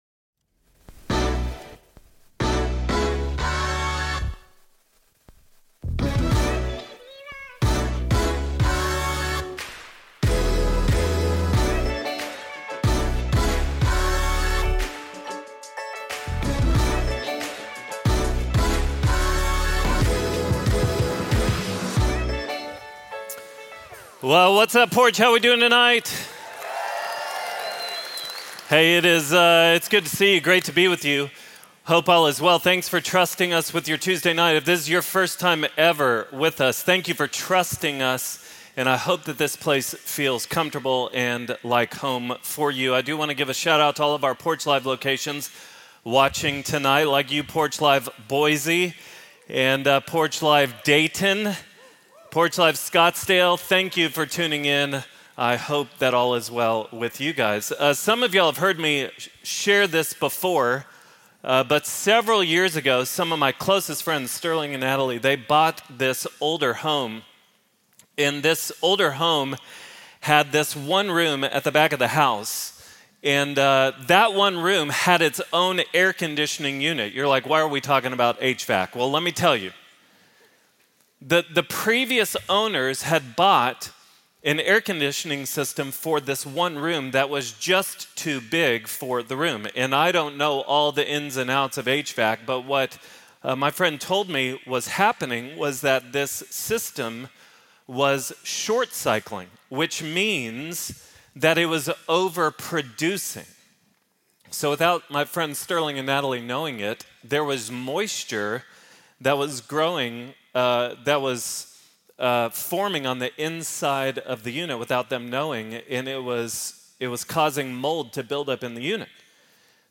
Religion & Spirituality, Christianity